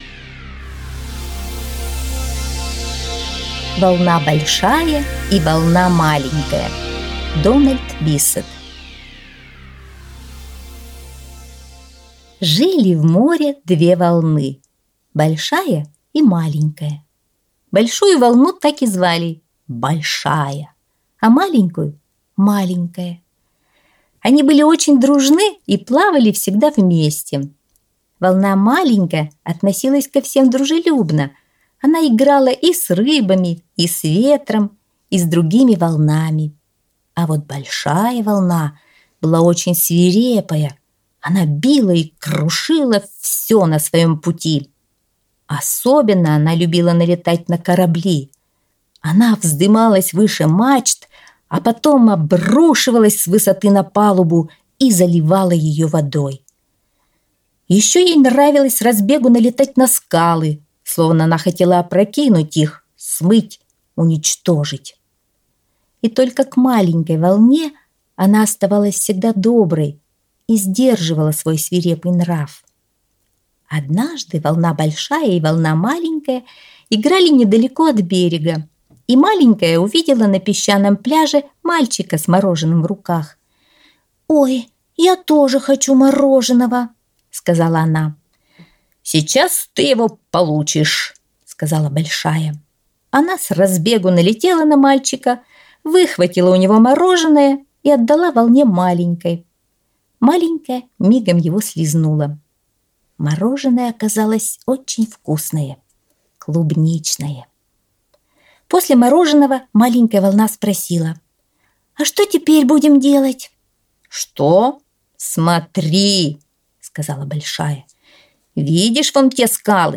Аудиосказка «Волна Большая и волна Маленькая»